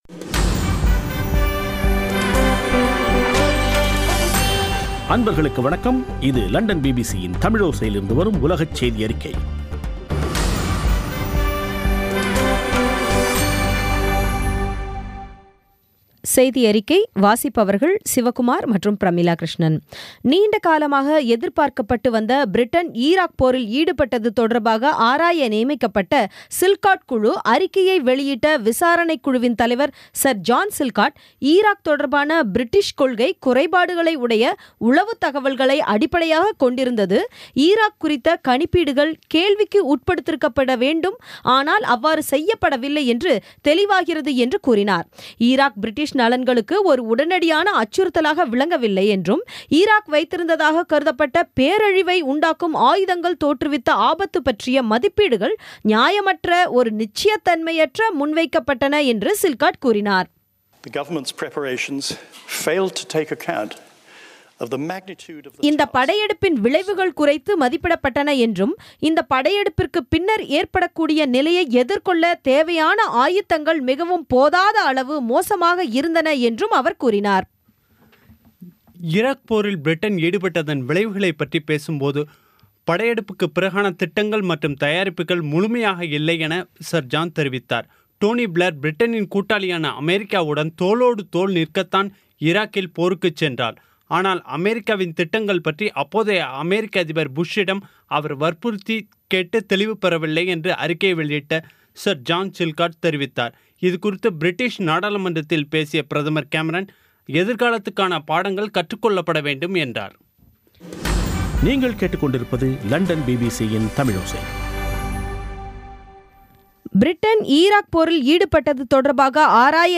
பி பி சி தமிழோசை செய்தியறிக்கை (06/07/2016)